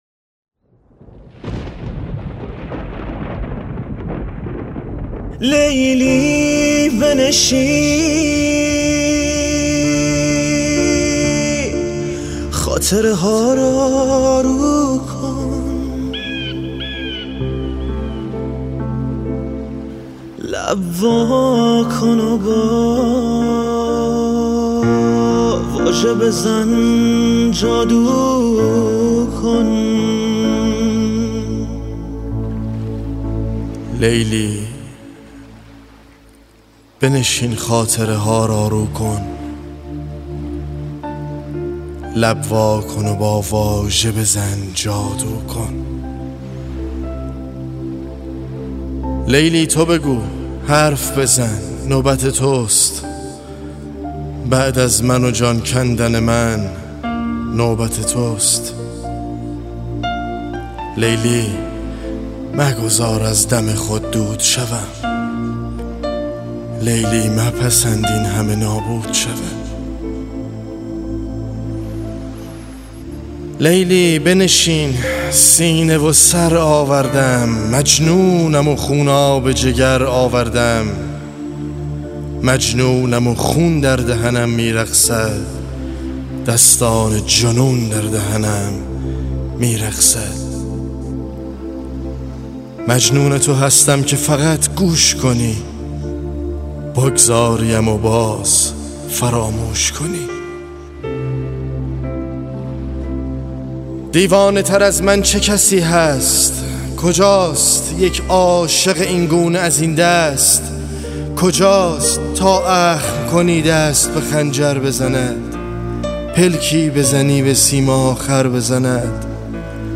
دانلود دکلمه هم مرگ با صدای علیرضا آذر
گوینده :   [علیرضا آذر]